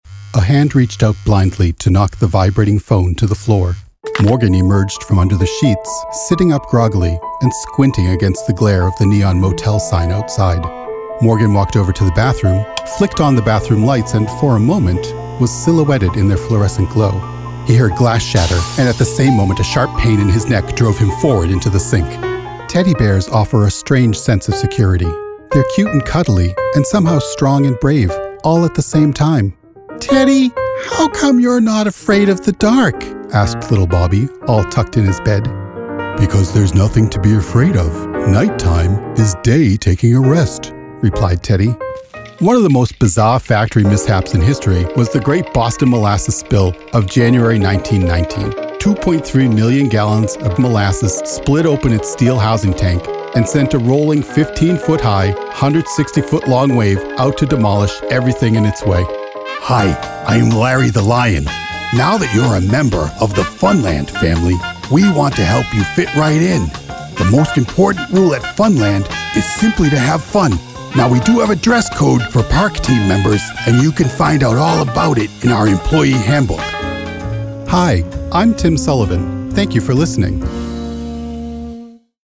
An intelligent, neighborly voice.
Narrative Demo
General American, Boston, New England